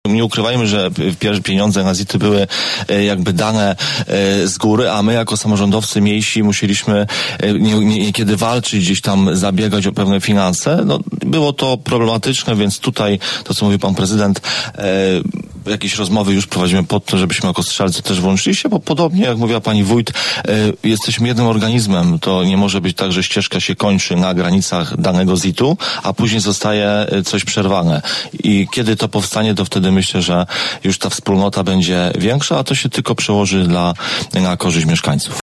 Burmistrz Strzelec Krajeńskich Mateusz Feder podkreśla, że dotąd w Lubuskiem na milionach z ZIT-ów korzystały Gorzów oraz Zielona Góra wraz z gminami przyległymi: